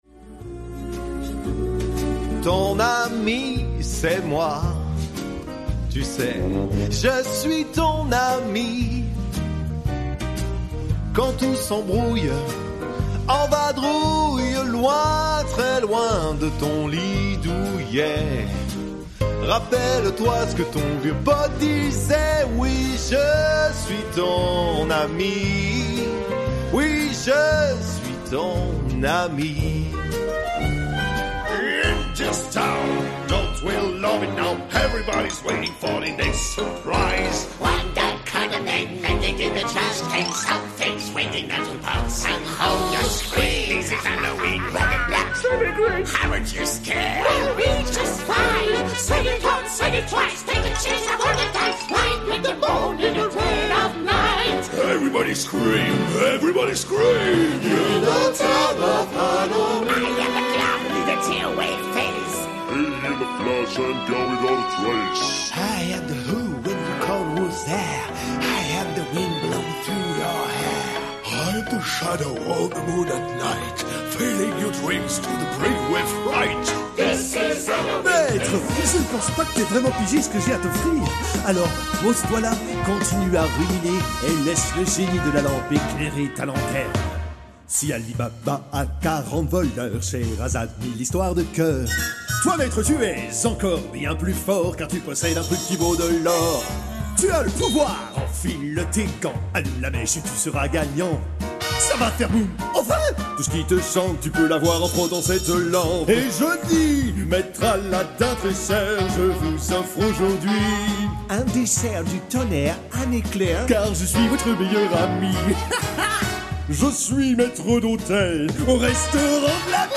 I am a professional french voicer over from 5 years with a smooth young voice, with some pretty bass; i can easily add modulations on my voice.
Isolated Cabin